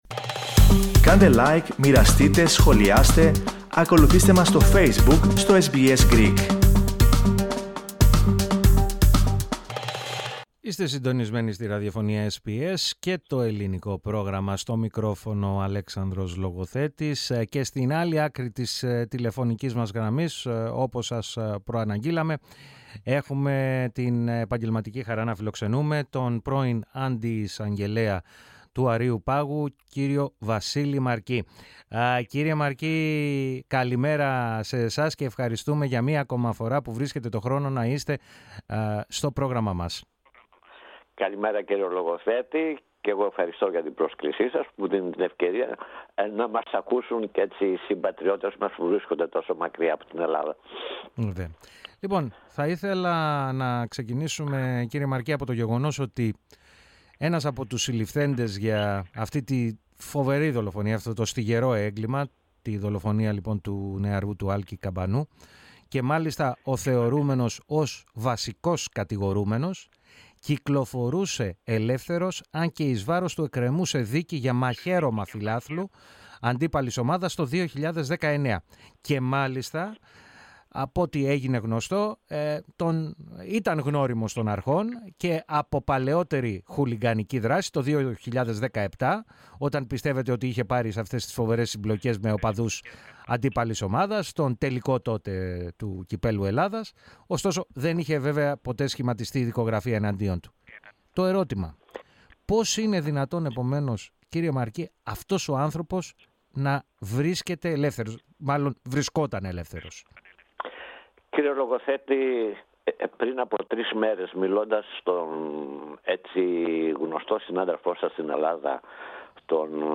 μίλησε στο Ελληνικό Πρόγραμμα της ραδιοφωνίας SBS, ο πρώην αντιεισαγγελέας του Αρείου Πάγου, Βασίλης Μαρκής.